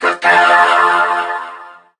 mech_crow_ulti_vo_01.ogg